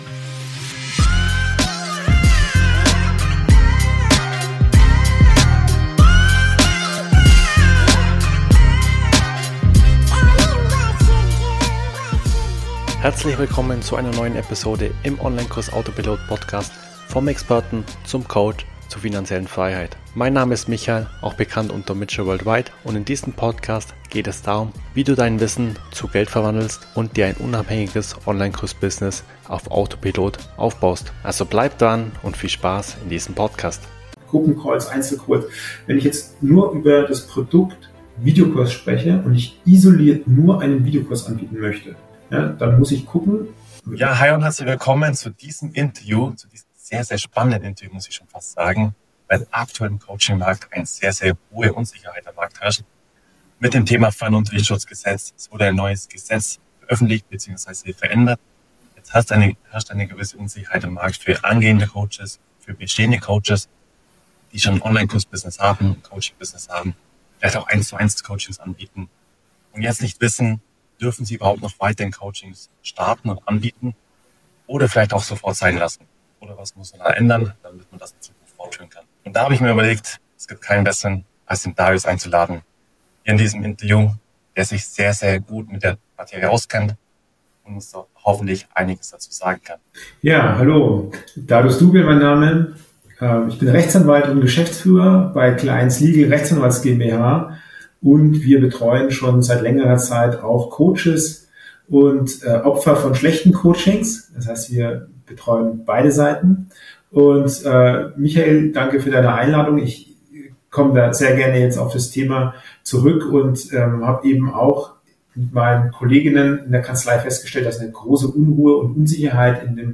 Im Interview haben wir Klarheit geschaffen, welche Angebote 2025 noch problemlos möglich sind – und wo du aufpassen musst.